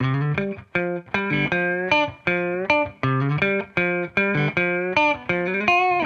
Index of /musicradar/sampled-funk-soul-samples/79bpm/Guitar
SSF_TeleGuitarProc2_79A.wav